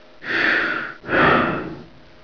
gasm_breath2.wav